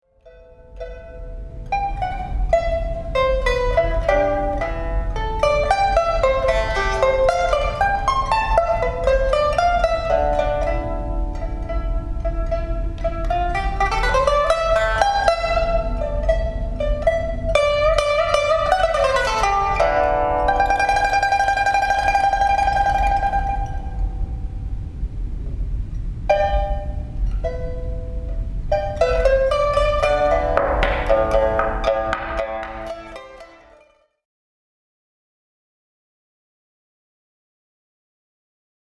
Pipa